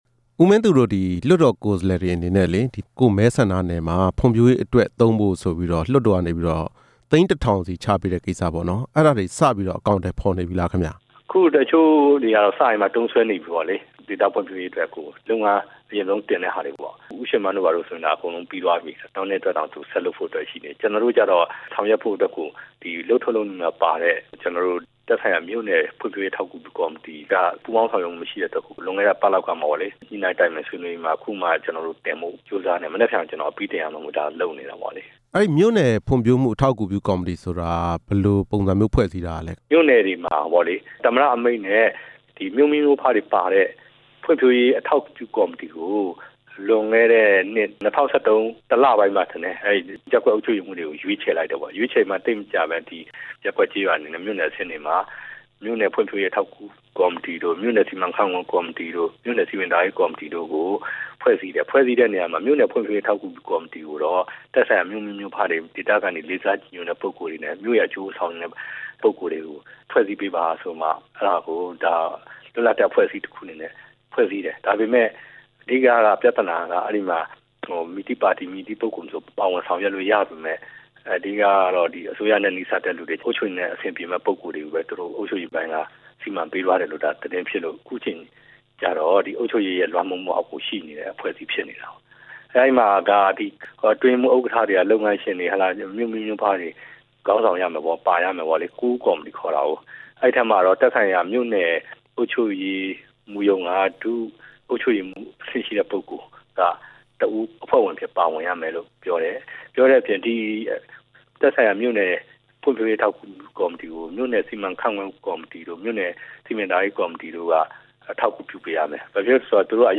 ဖွံ့ဖြိုးရေးအစီအစဉ်အတွက် ငွေများကိုသုံးစွဲမှု ဆက်သွယ်မေးမြန်းချက်